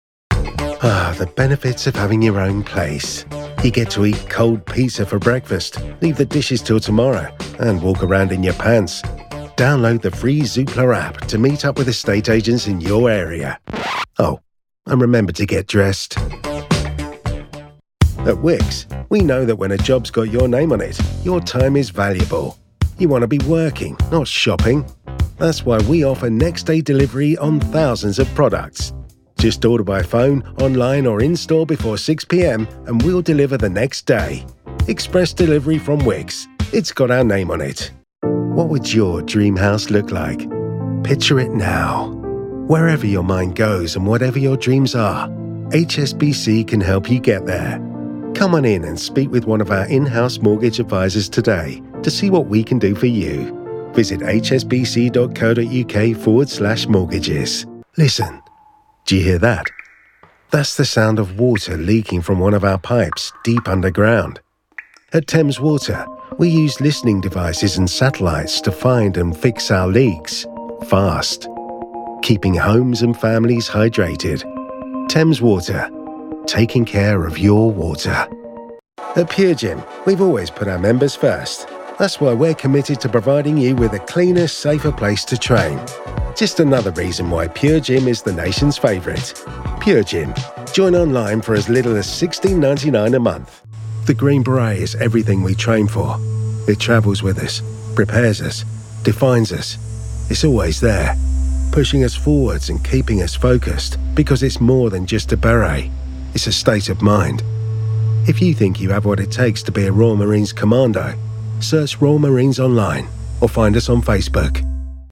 Englisch (Britisch)
Tief, Natürlich, Zugänglich, Freundlich, Warm
Telefonie